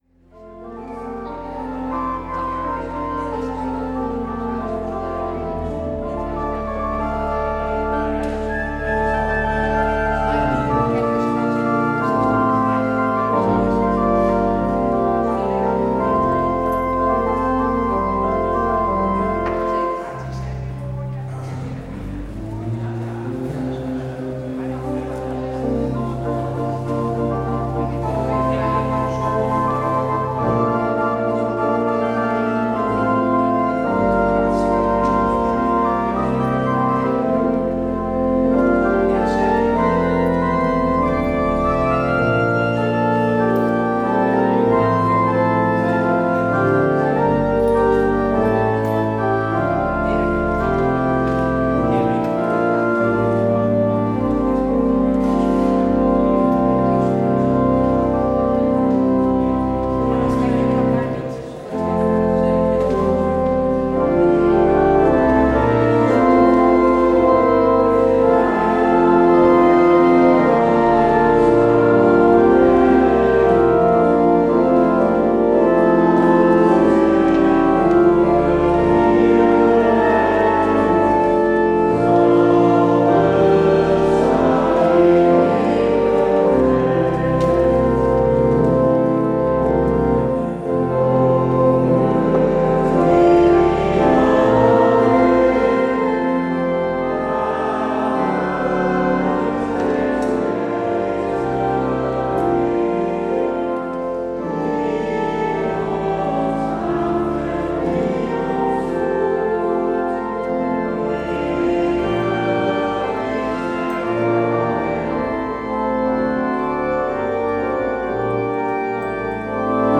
Het slotlied is: NLB 146c: 1, 3 en 7.